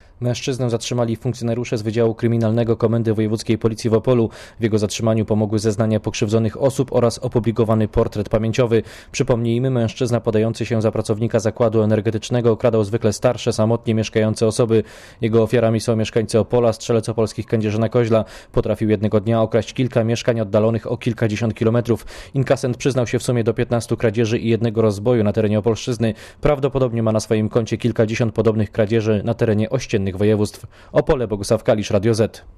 Mówi reporter Radia Zet (990Kb)